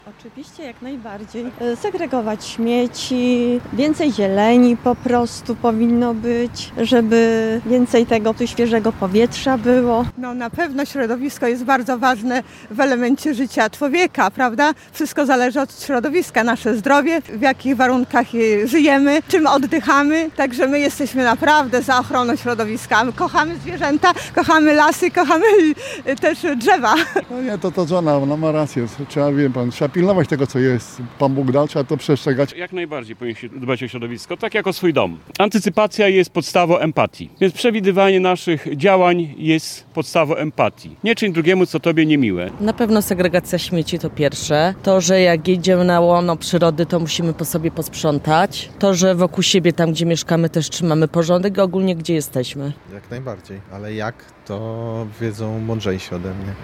Sonda: Jak mieszkańcy Łomży chcą dbać o środowisko?
Osoby, z którymi rozmawiał nasz reporter, są zgodne: powinniśmy chronić środowisko.